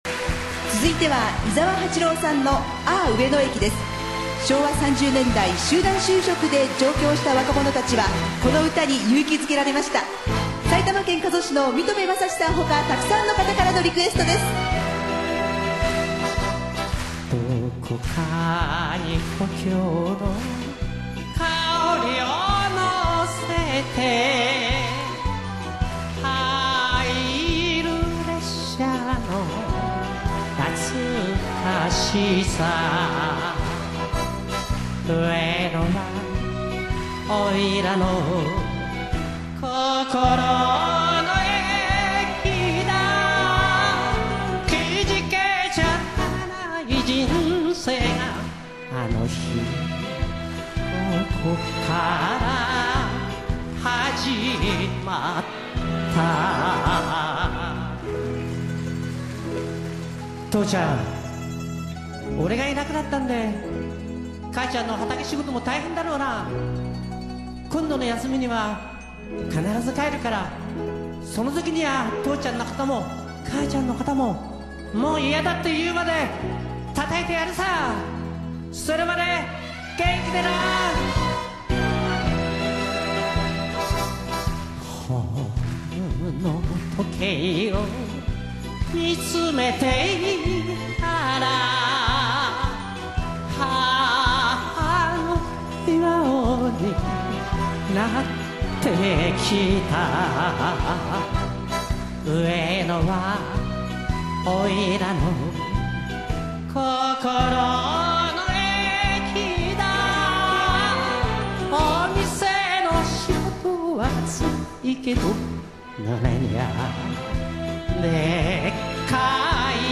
駅にまつわる歌謡曲